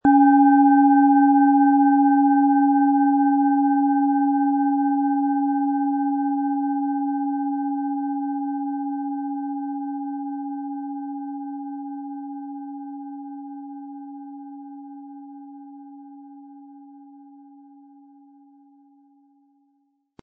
Es ist eine von Hand gearbeitete tibetanische Planetenschale Pluto.
Lieferung mit richtigem Schlägel, er lässt die Planetenschale Pluto harmonisch und wohltuend schwingen.
MaterialBronze